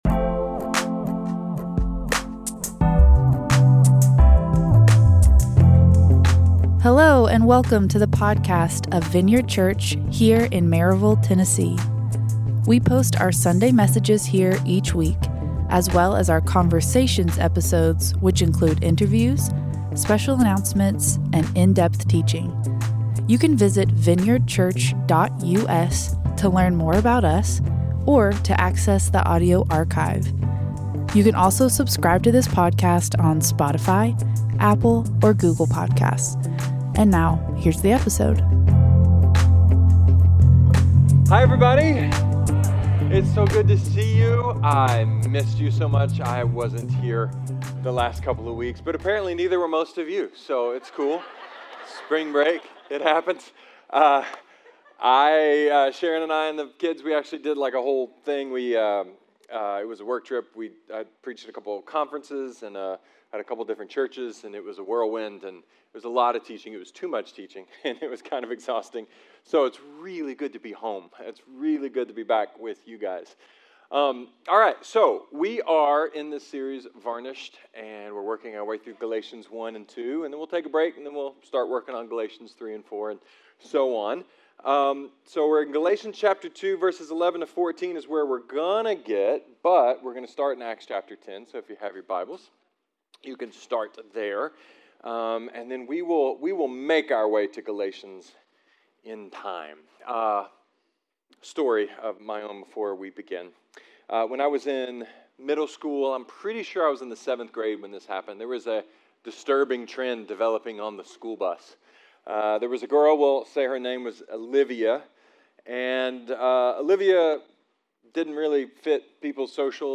A sermon about being the hero, and then being the chump… and why sinful people like us have to keep chasing greatness.